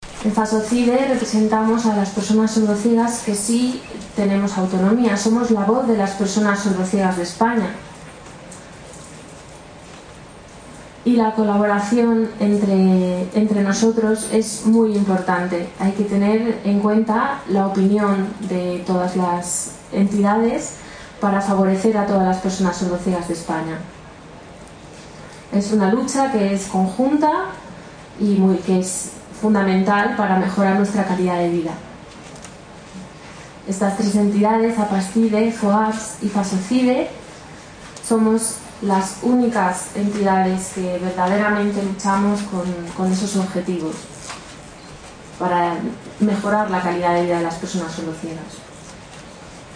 El acto central se celebró en el Complejo Deportivo y Cultural de la ONCE en Madrid, con el lema “Caminando sin barreras”.
a través de su guía-intérprete